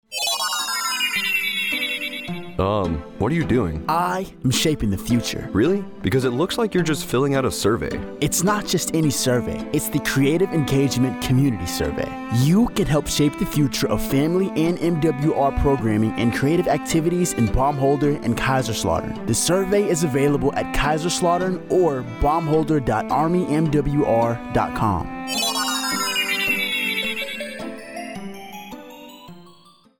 Radio Spot - Community Survey AFN Kaiserslautern